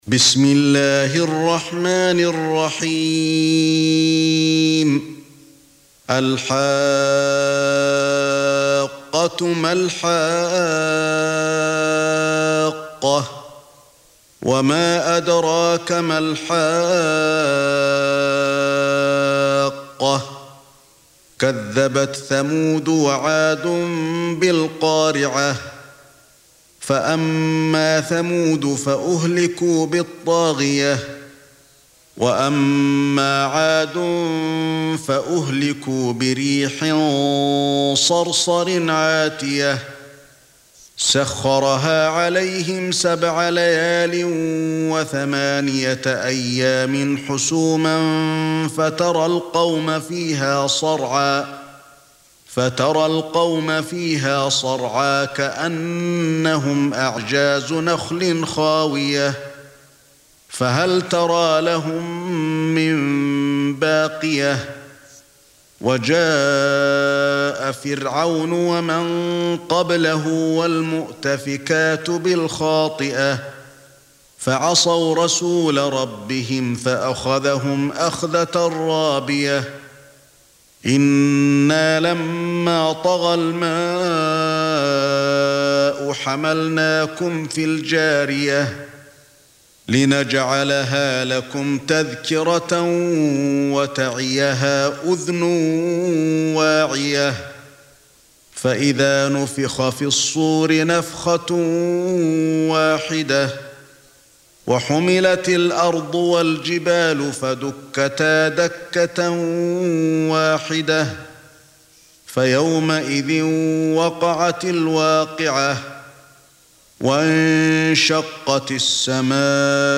Surah Sequence تتابع السورة Download Surah حمّل السورة Reciting Murattalah Audio for 69.